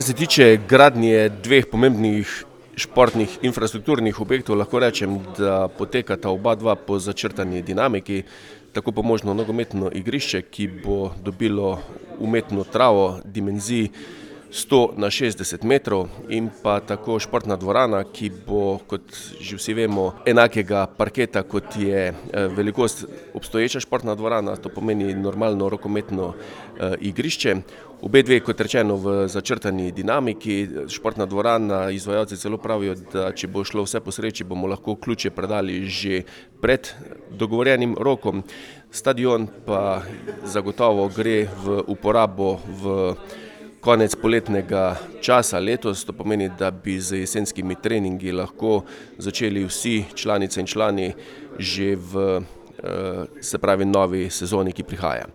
O tem, kako daleč so dela na obeh projektih, smo povprašali župana Mestne občine Slovenj Gradec Tilna Kluglerja:
IZJAVA KLUGLER 1.mp3